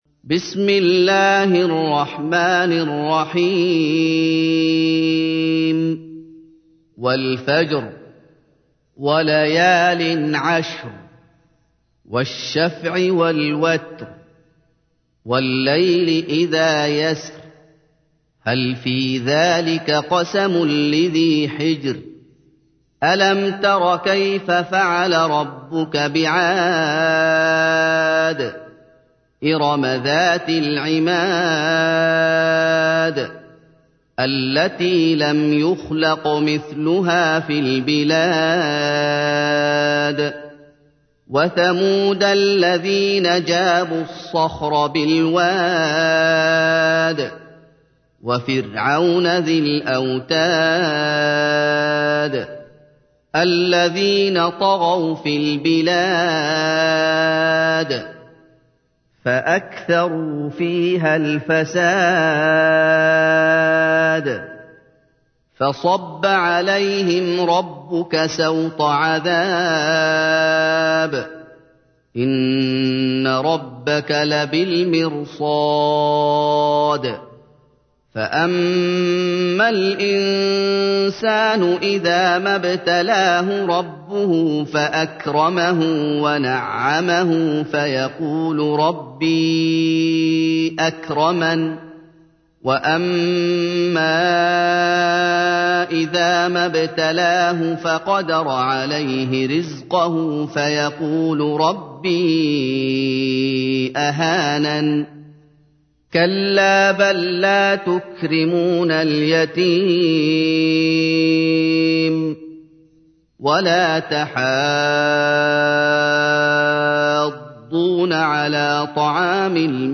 تحميل : 89. سورة الفجر / القارئ محمد أيوب / القرآن الكريم / موقع يا حسين